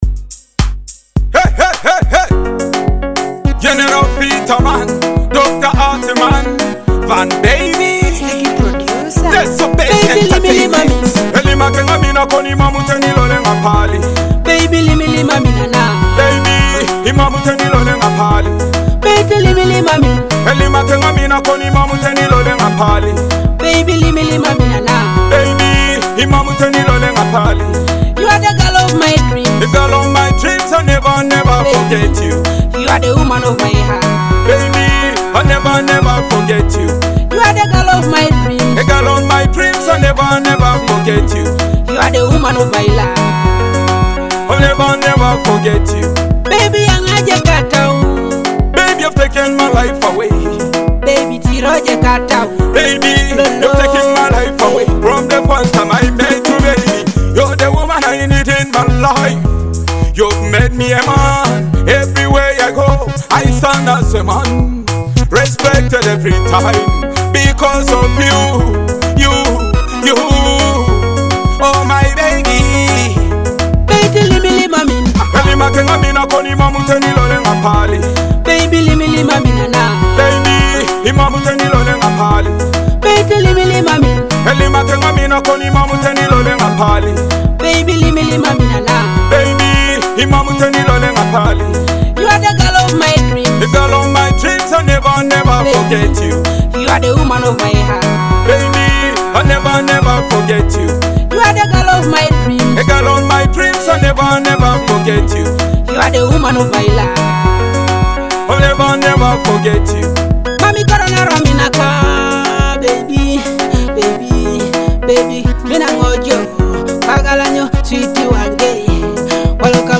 a captivating blend of rhythmic beats and powerful vocals.